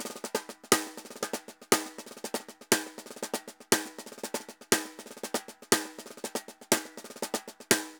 Tambor_Baion 120_1.wav